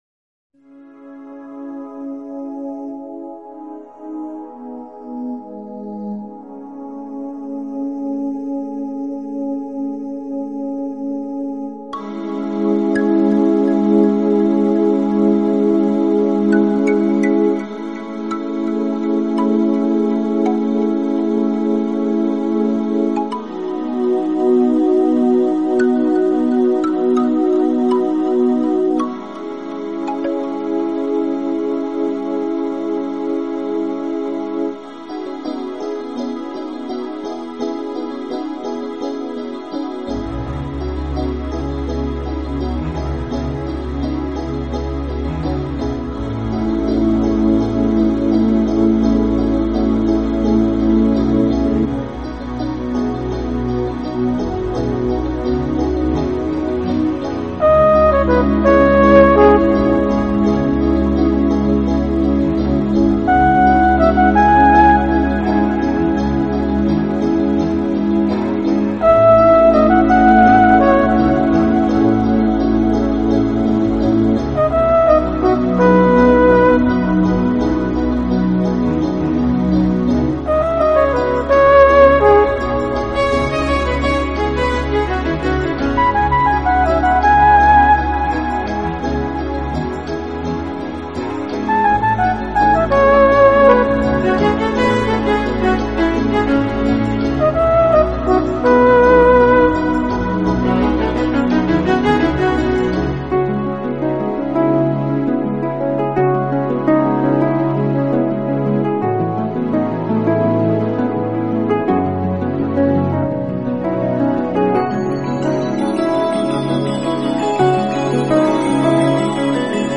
【新世纪音乐】